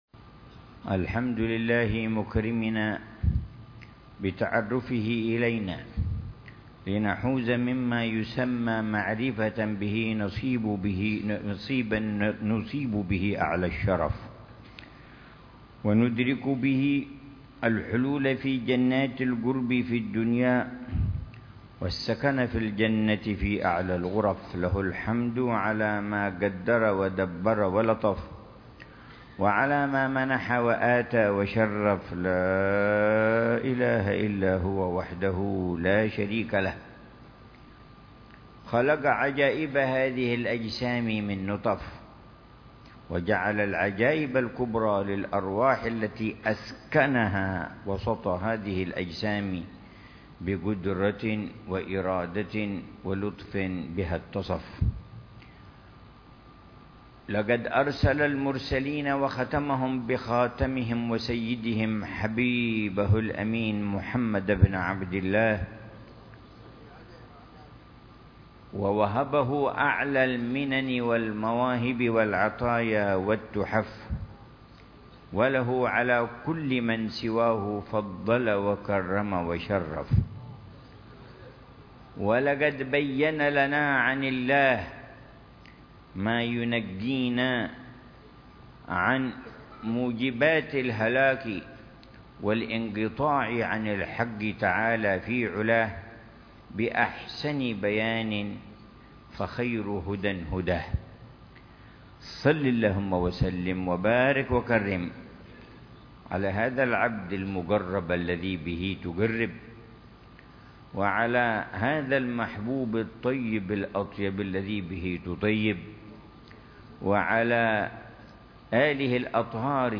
تفسير الحبيب العلامة عمر بن محمد بن حفيظ للآيات الكريمة من سورة الحشر، ضمن الدروس الصباحية لشهر رمضان المبارك لعام 1442، من قوله تعالى: